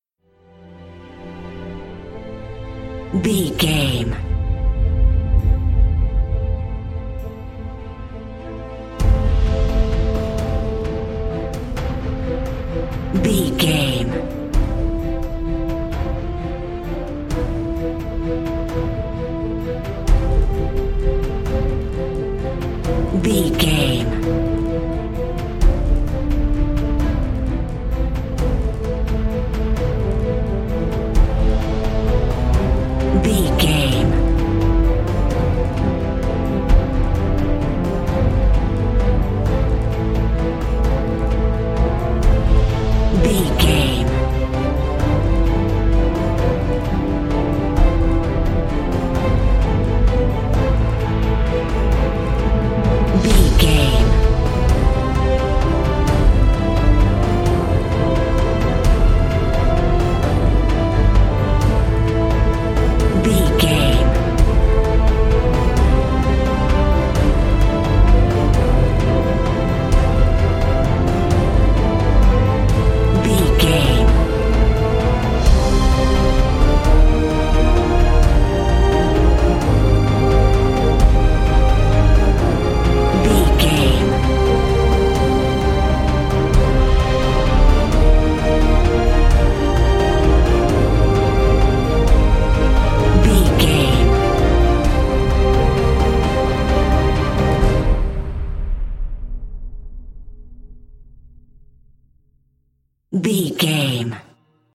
Epic / Action
Aeolian/Minor
strings
percussion
horns
synth effects
driving drum beat